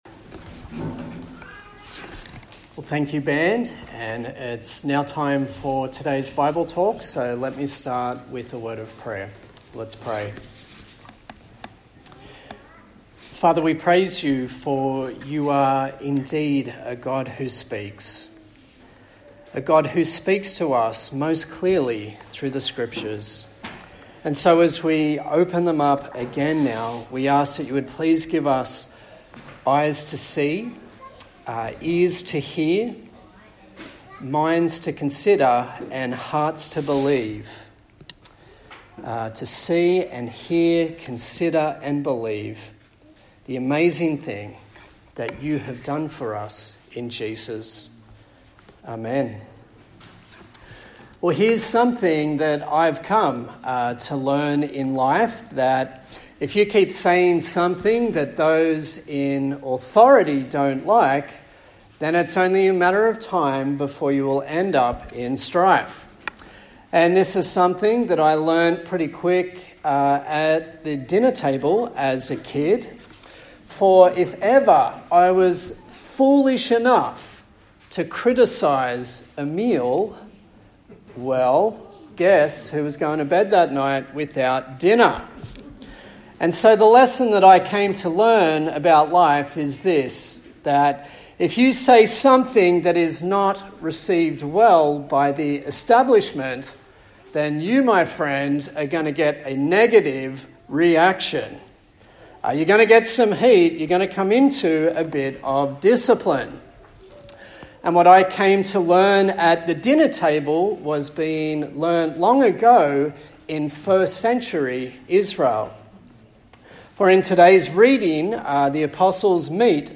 Service Type: Sunday Morning A sermon in the series on the book of Acts